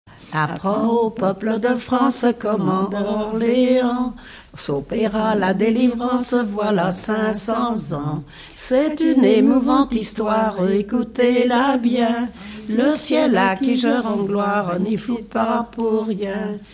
en duo
Pièce musicale inédite